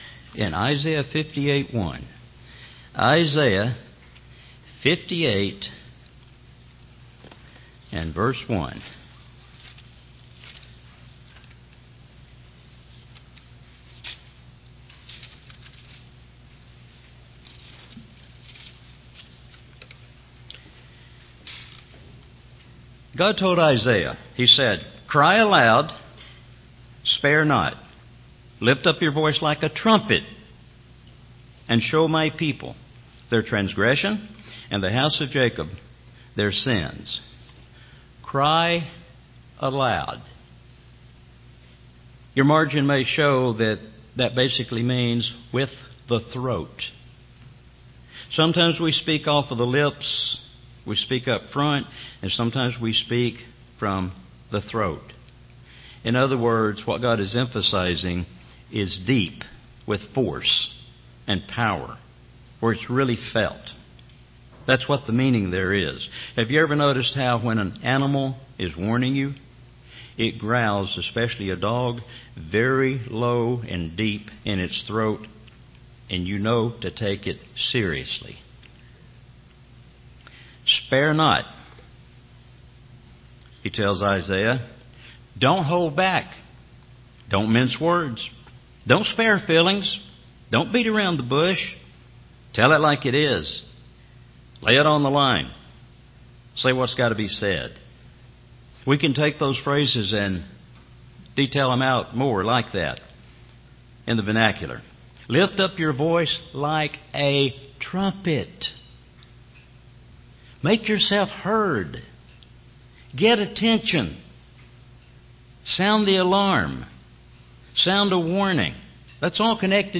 Learn of many of the Elijah's including the final Elijah to come. There are so many amazing revelations in this sermon you just need to listen.